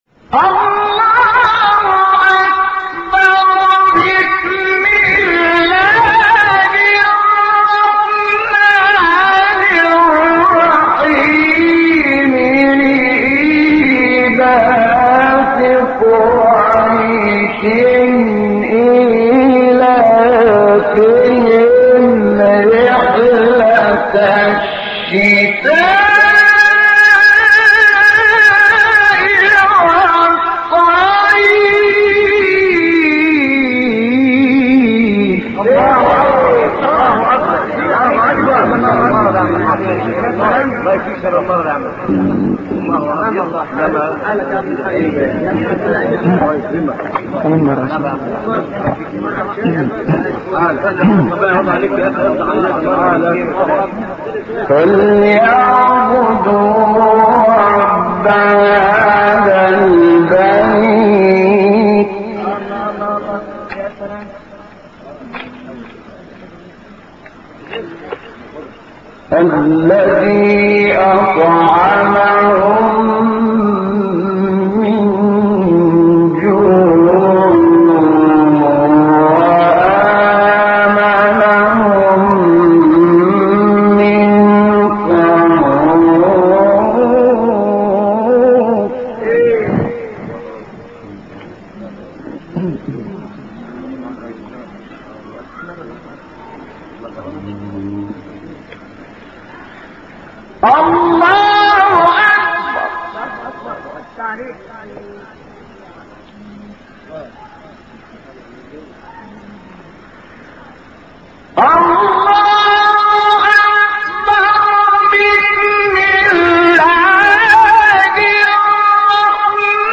تلاوت سوره قریش استاد شحات | نغمات قرآن
سوره : قریش آیه: تمام سوره استاد : شحات محمد انور مقام : سه گاه قبلی بعدی